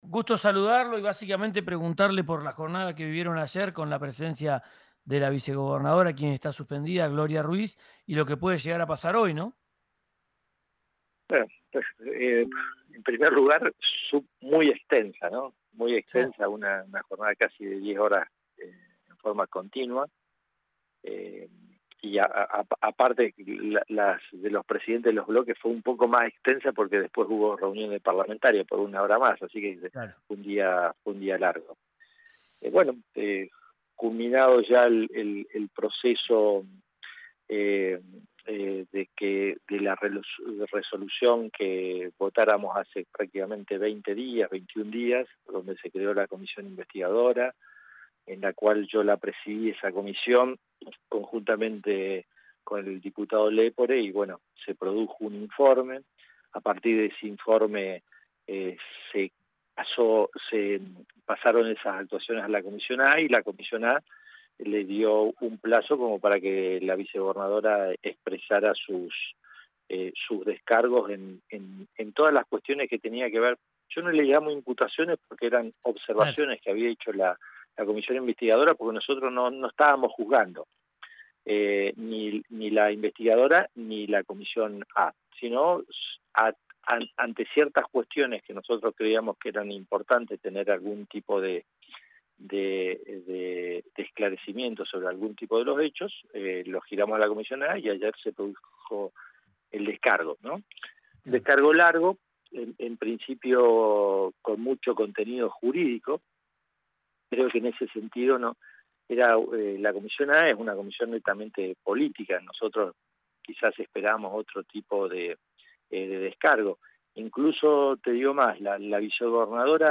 Escuchá a Guillermo Monzani, en RADIO RÍO NEGRO: